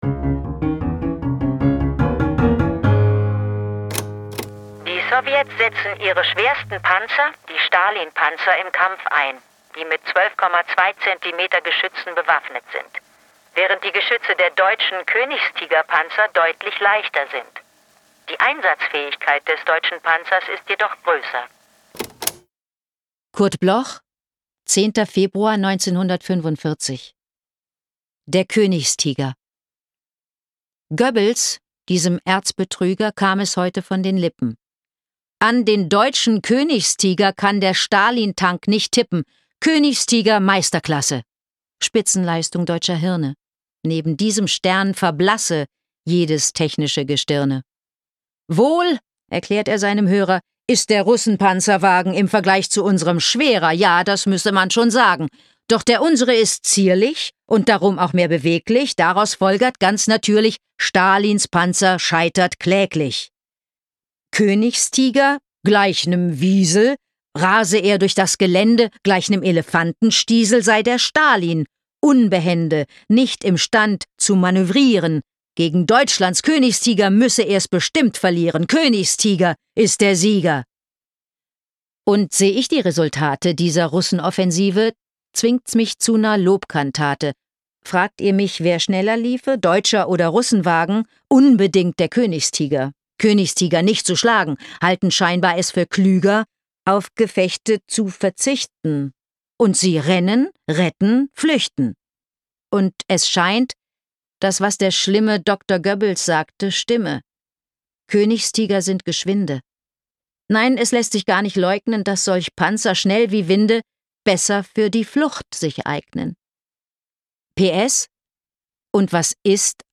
vorgetragen von Anke Engelke
Anke-Engelke-Der-Koenigstiger_mit-Musik.m4a